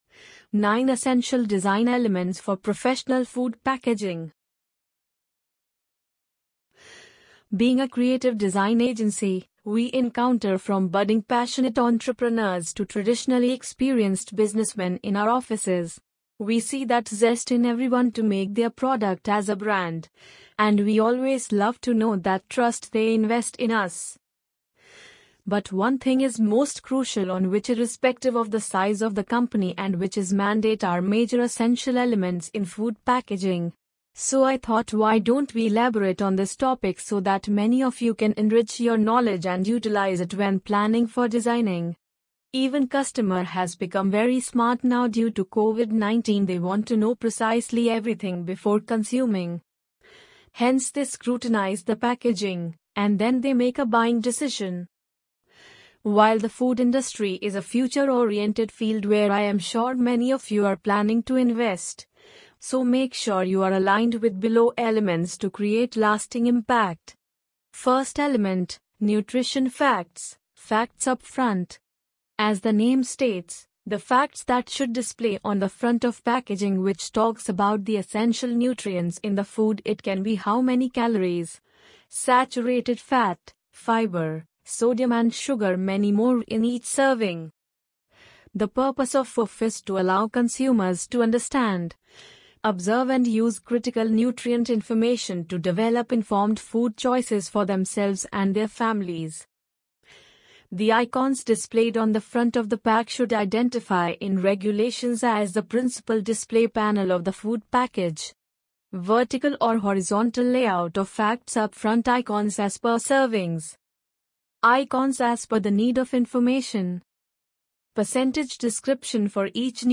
amazon_polly_5425.mp3